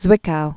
(zwĭkou, tsvĭk-)